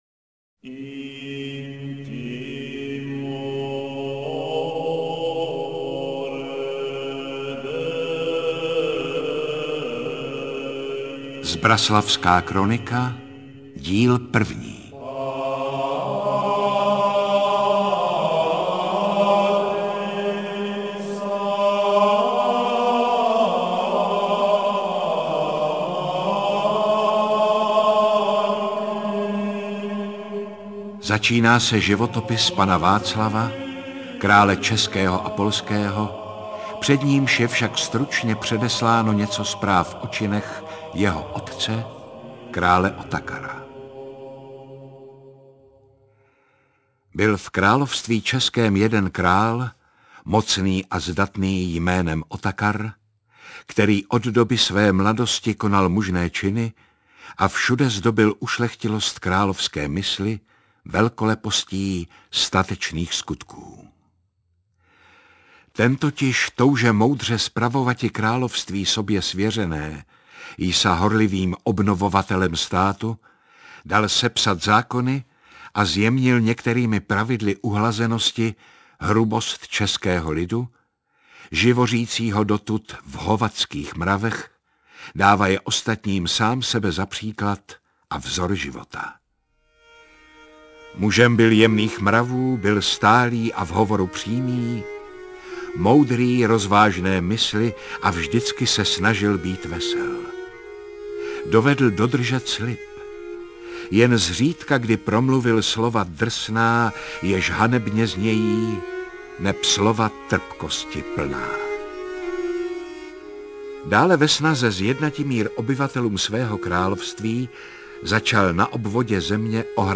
Interpret:  Jaromír Meduna
Četba z latinské kroniky zbraslavských opatů Oty a Petra Žitavského od počátku vlády krále Václava II. až po nástup Karla IV. na český trůn.
AudioKniha ke stažení, 10 x mp3, délka 4 hod. 36 min., velikost 252,4 MB, česky